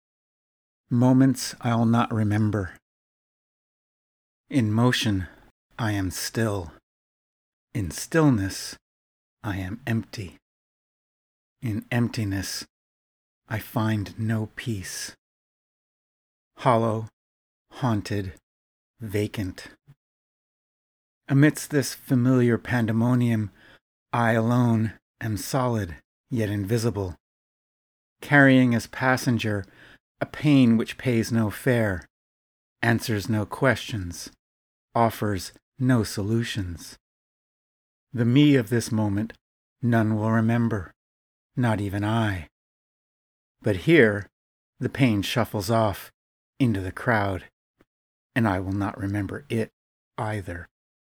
Readings available as a podcast: